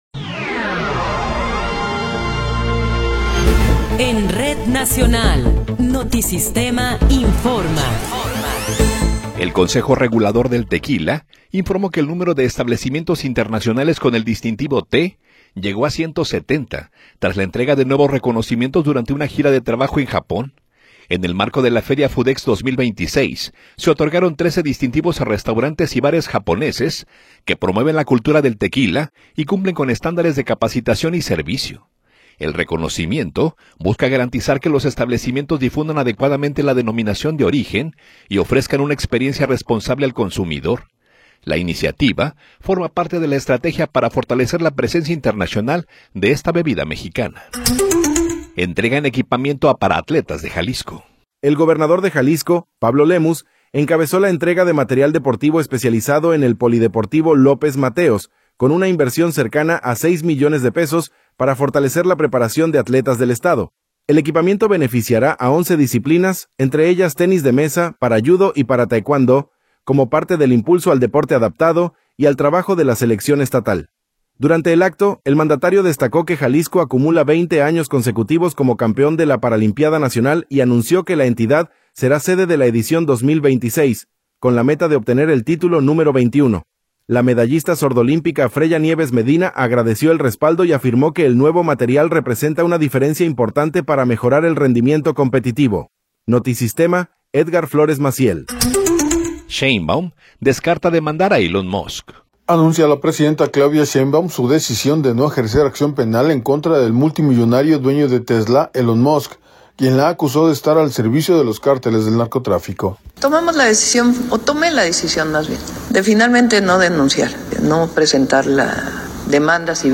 Noticiero 19 hrs. – 14 de Marzo de 2026
Resumen informativo Notisistema, la mejor y más completa información cada hora en la hora.